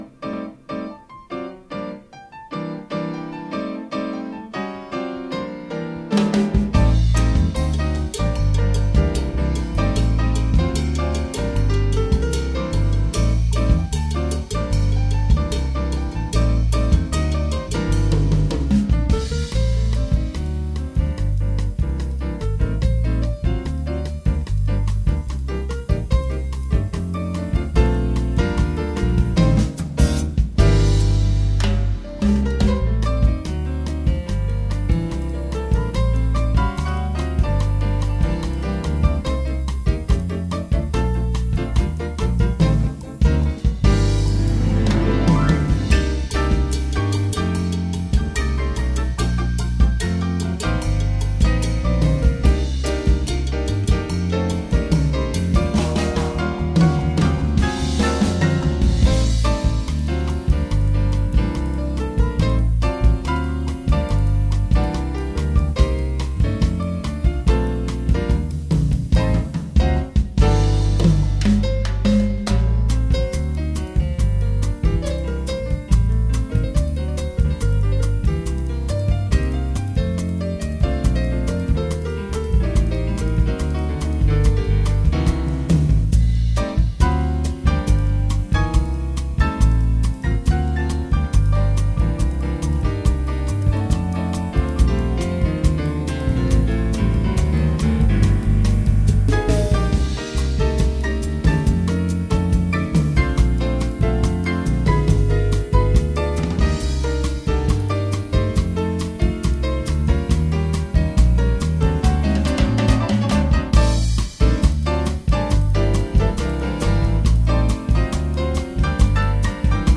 Jazz koncert
klaviature
kontrabas
tolkala